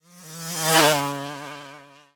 car8.ogg